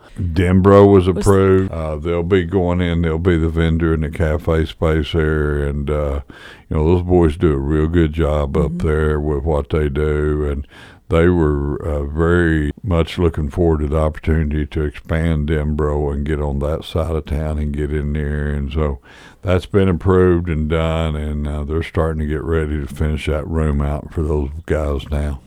Mayor Adams says the selected vendor for the cafe area will be Denbro Coffee and Custard.